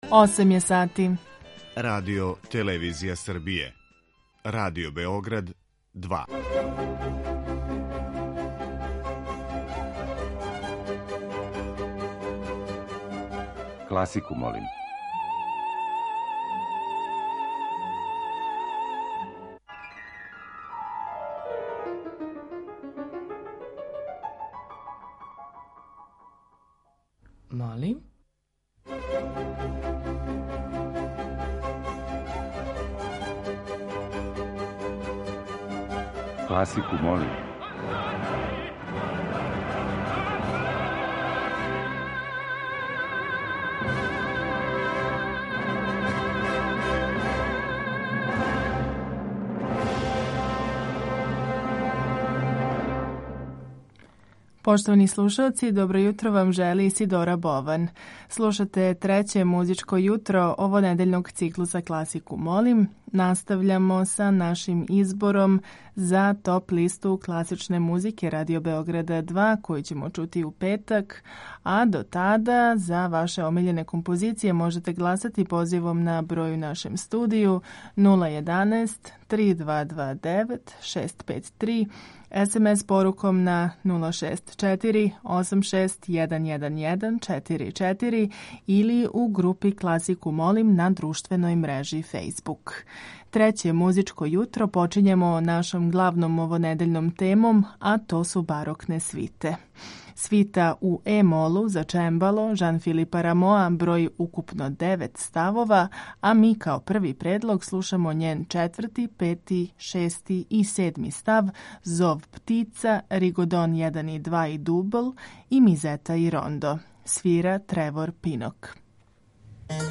Барокне свите